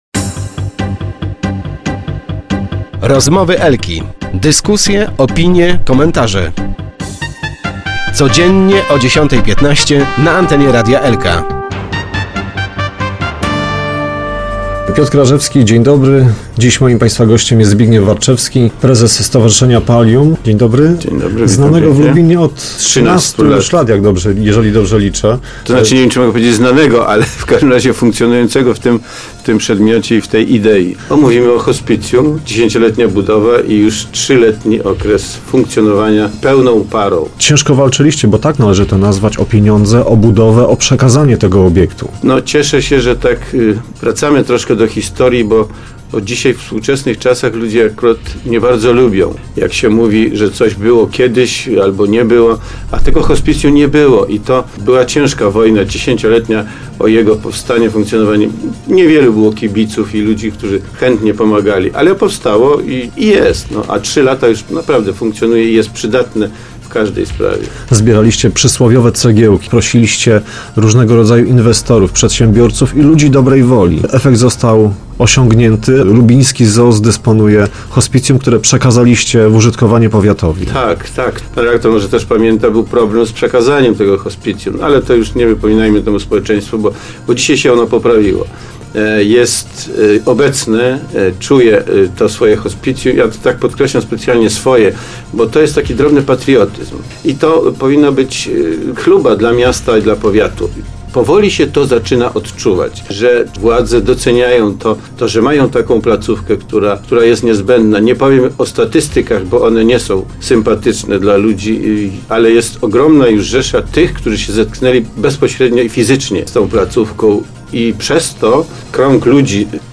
Start arrow Rozmowy Elki arrow Palium z dalsza misją rozbudowy hospicjum